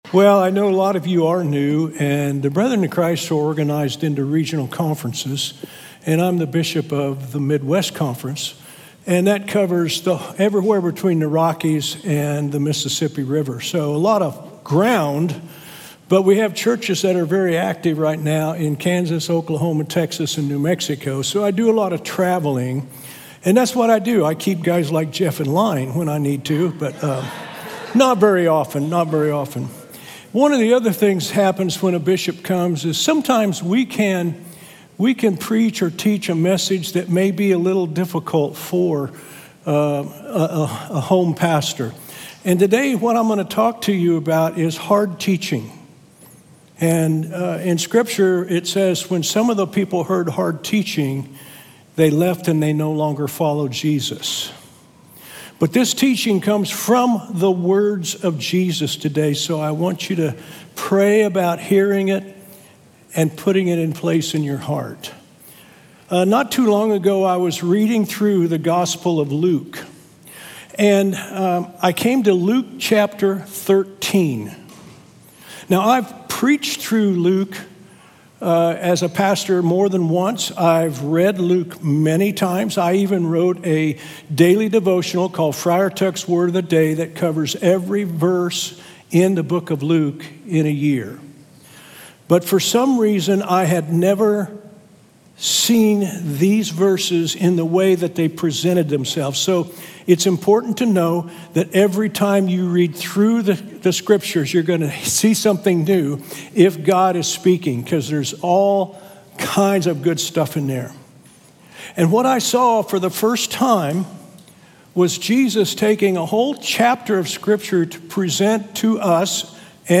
A sermon from the series "Revolution Sermon."